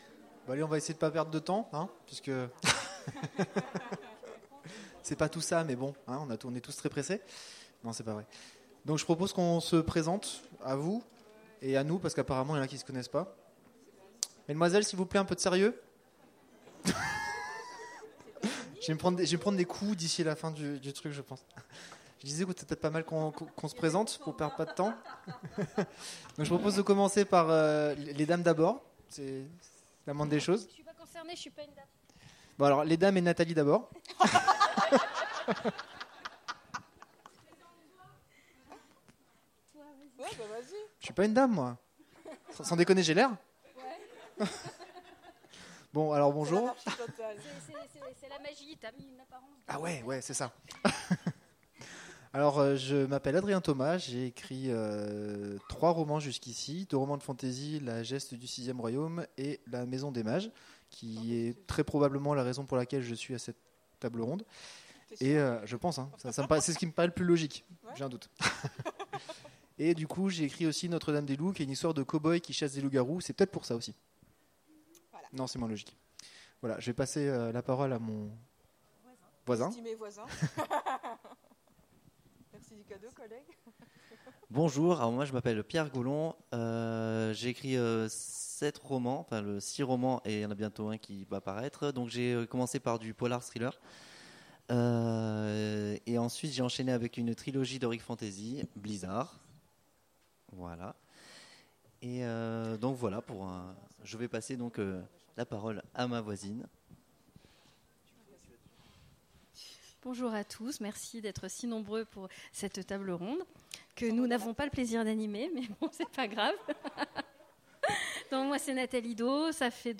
Imaginales 2016 : Conférence Magie, mages, magiciens…